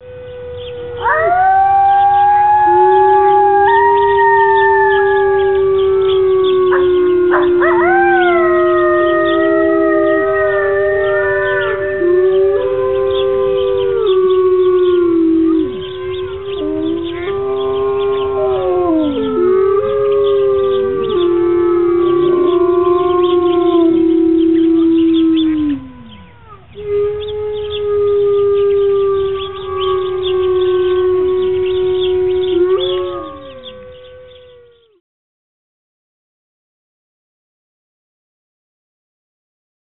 الالات واصوات